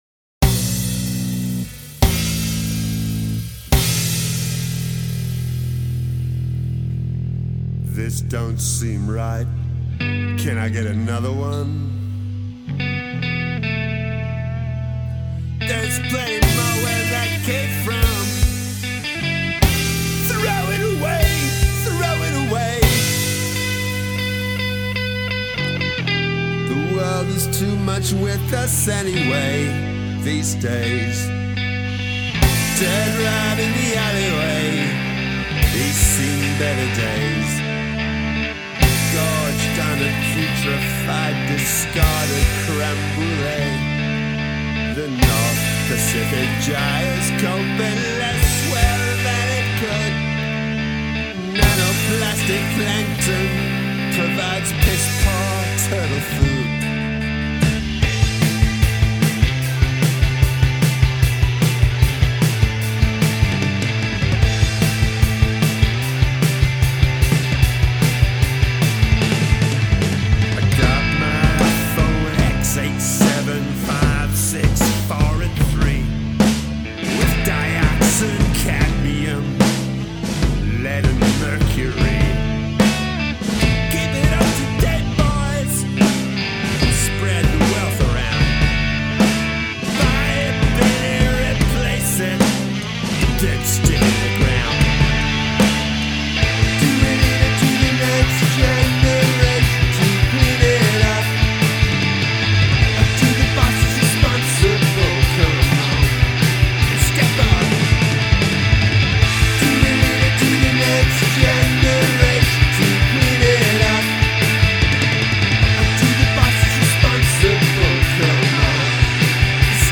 Have a guest play a household item on the track
Excellent dynamics.